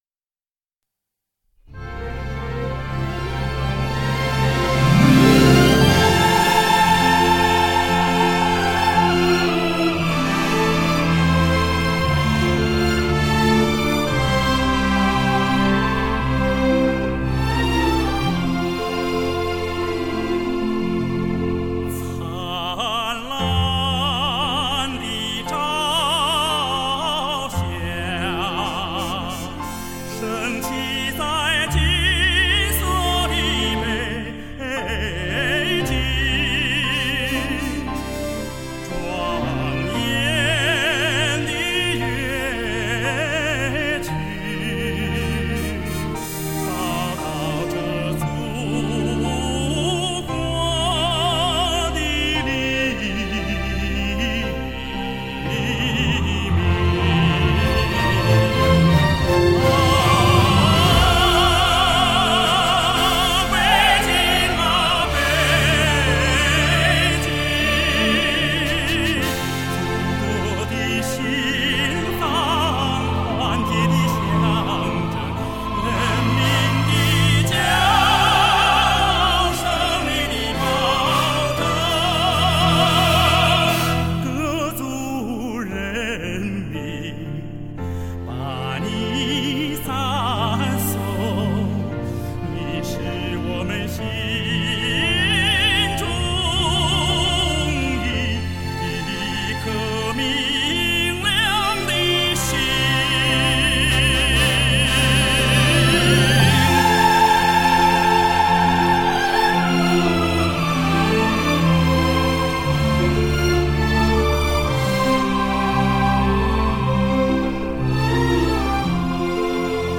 甜美柔润
中低声区流畅舒展，高声区刚劲奔放而畅达华美，加之气息从容，强弱自如，分句完美。
中国第一男高音，帕瓦罗蒂的首位亚洲。